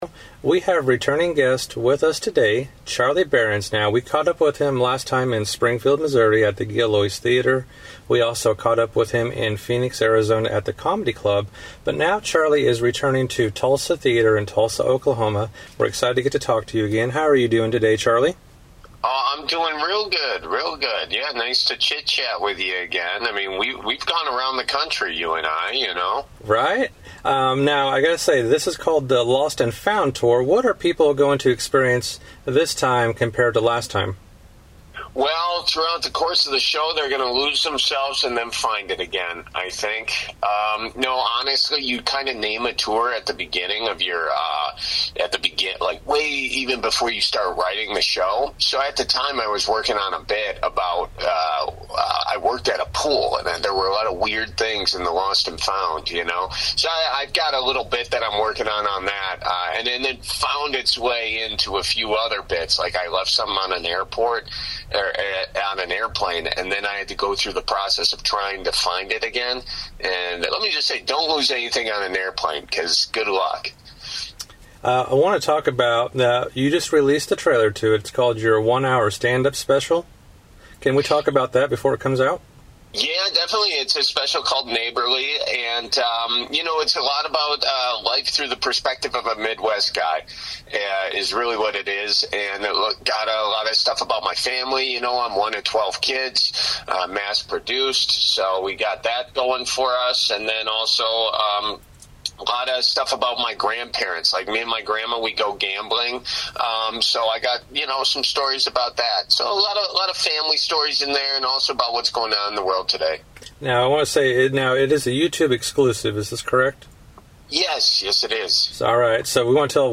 Exclusive Interview Segment Charlie Berens Talks Tulsa Oklahoma Stop